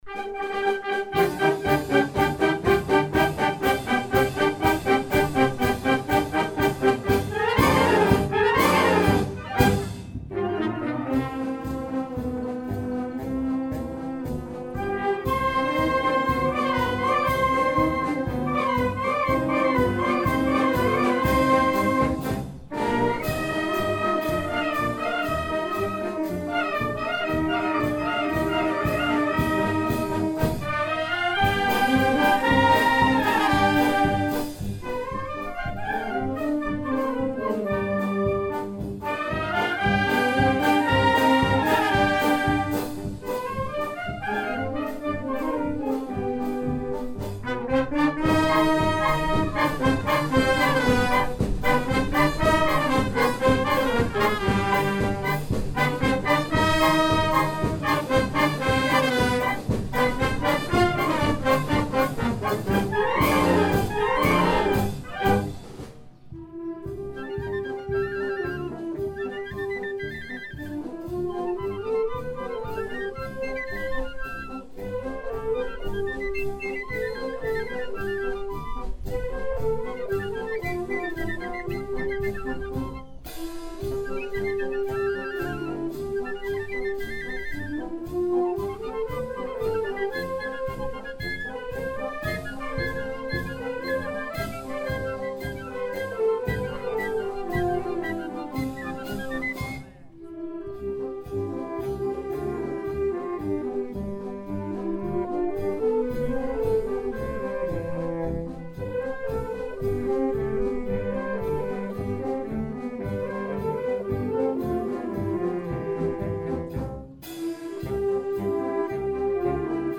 Juntament amb la Banda de Maó